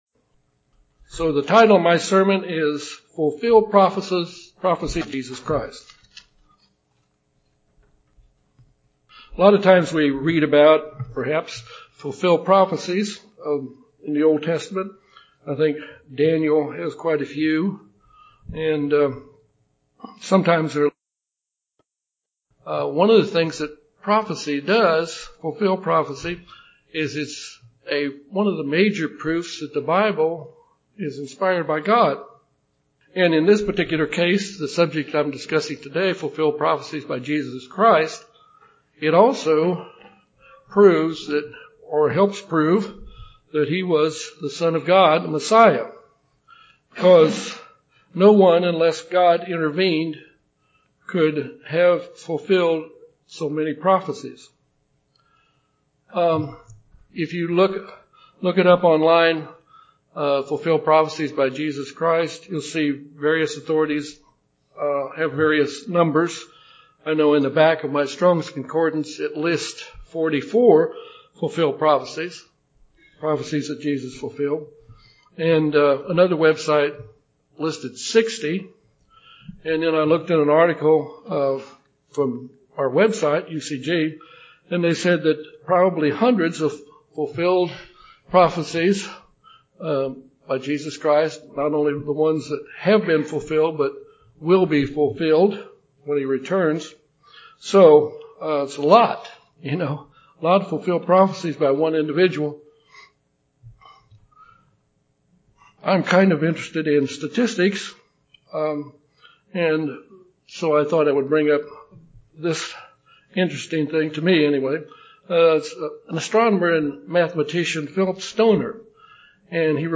In this case, it also helps prove that Jesus Christ was the promised Messiah. This sermon discusses eight of what Bible scholars say could be hundreds of prophecies that were or will be fulfilled by Jesus Christ.